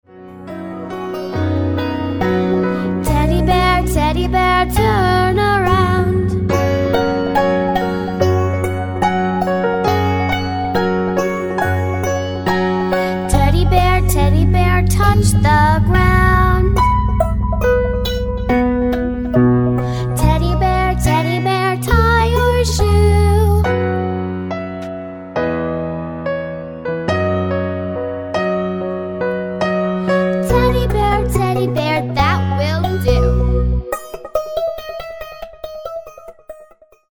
with vocal instruction